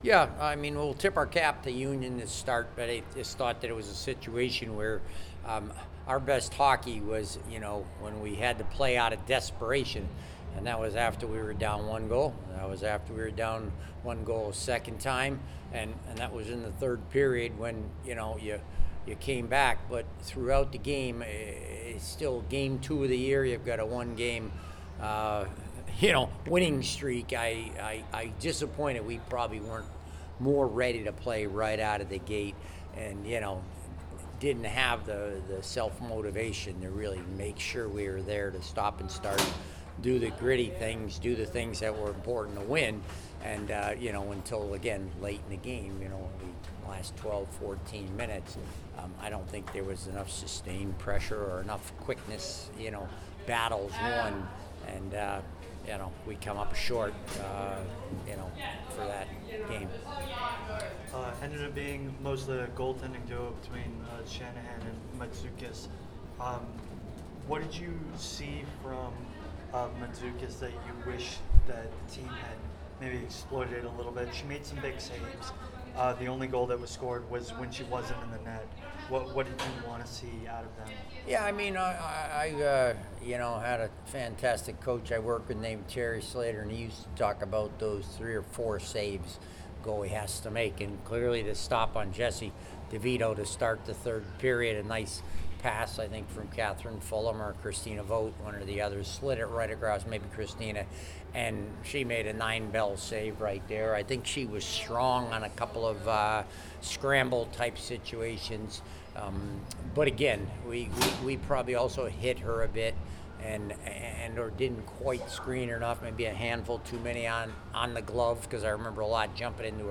Union Postgame Interview